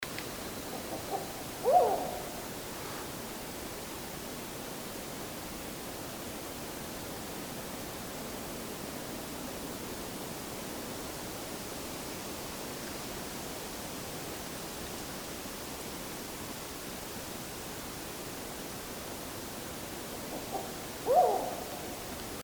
Black-banded Owl (Strix huhula)
Life Stage: Adult
Location or protected area: Parque Provincial Cruce Caballero
Condition: Wild
Certainty: Observed, Recorded vocal
strix_huhula_cruce.MP3